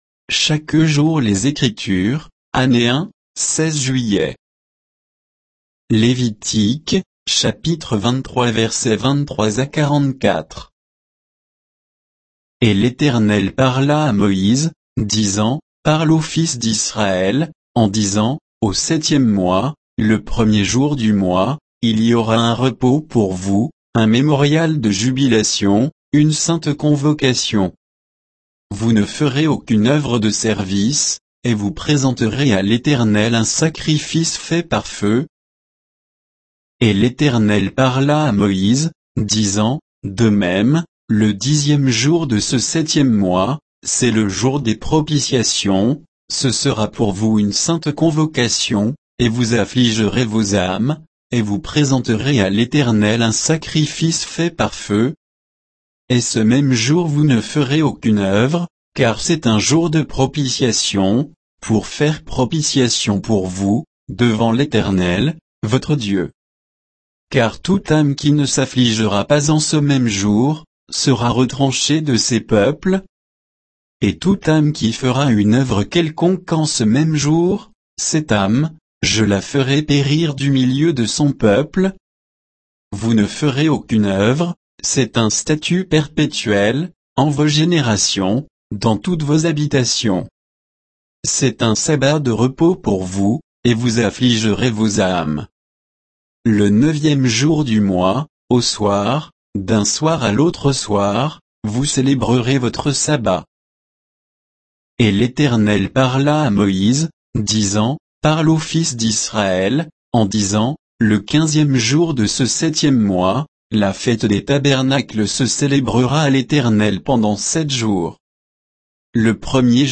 Méditation quoditienne de Chaque jour les Écritures sur Lévitique 23, 23 à 44